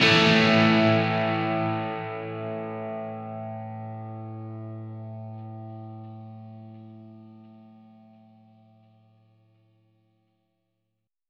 Indie Pop Guitar Ending 01.wav